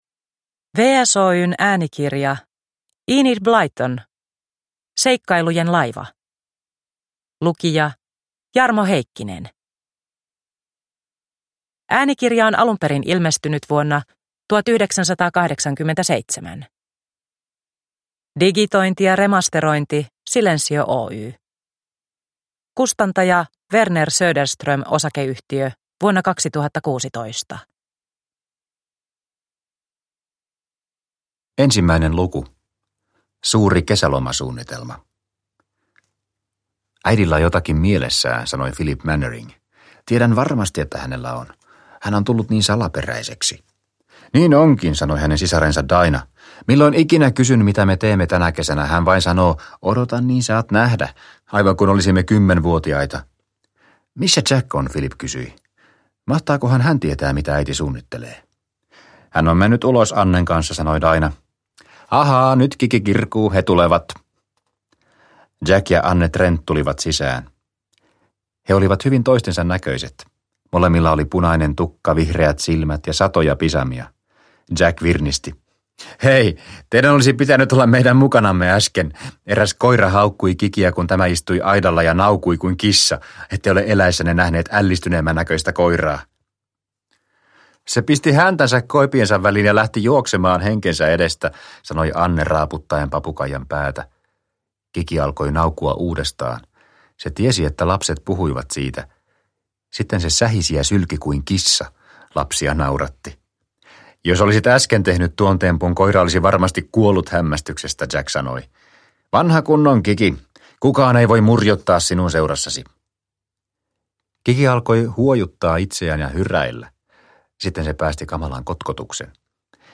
Seikkailujen laiva – Ljudbok – Laddas ner